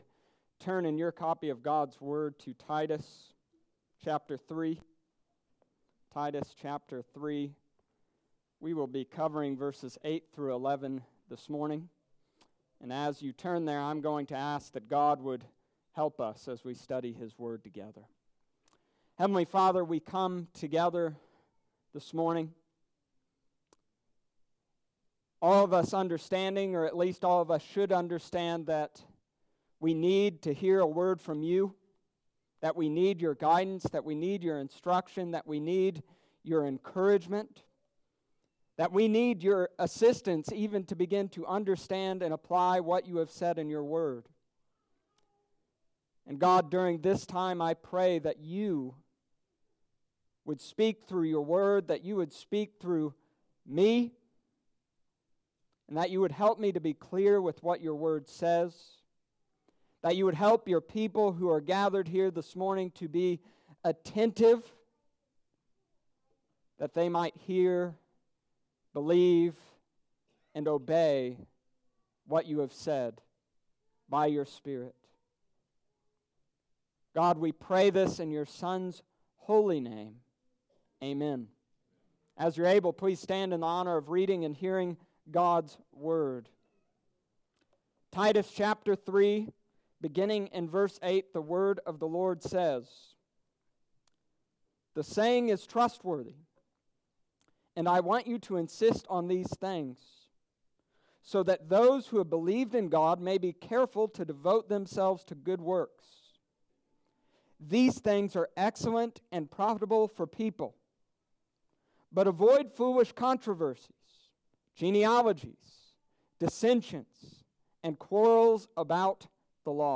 Stick to Sound Doctrine: Titus Chapter 3 verses 8-11. November 24th,2019 Sunday Morning Service.